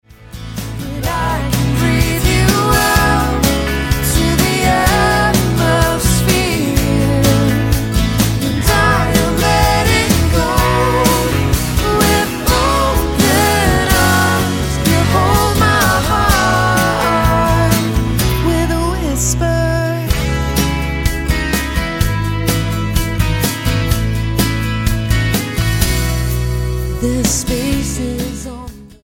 modern country duo
Style: Country